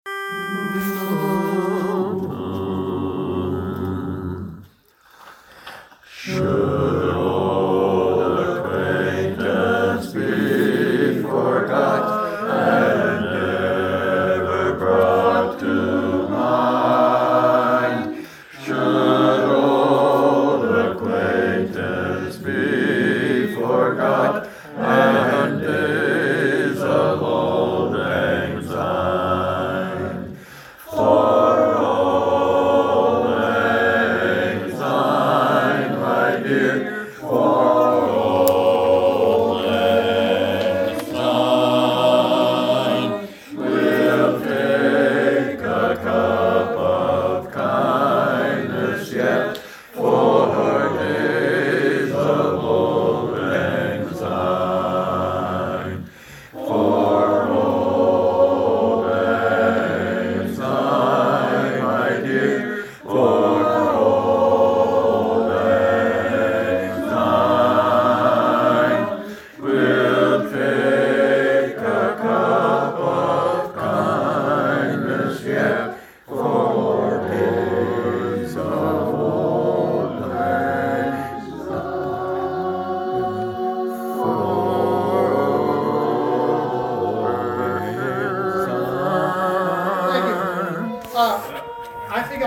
THESE ARE ALL THE SONGS WHICH WE HAVE PERFORMED OVER THE YEARS ALPHABETICALY